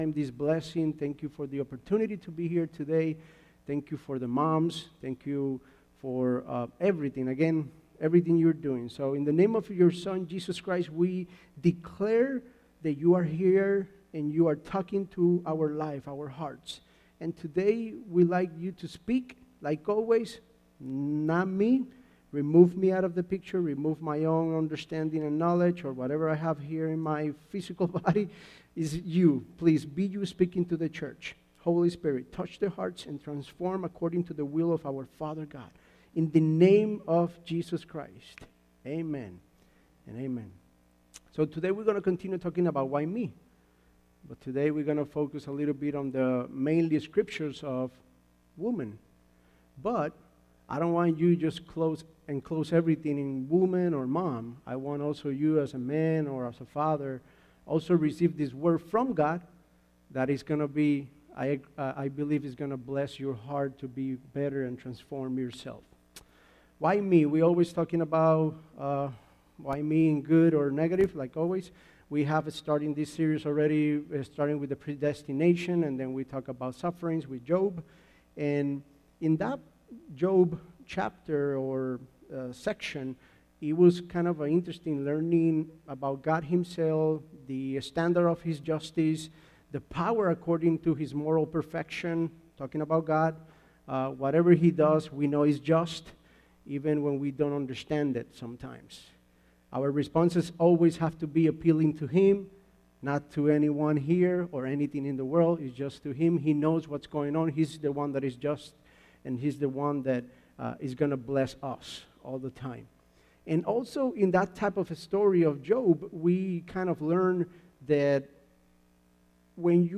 Sermons by FLC Elburn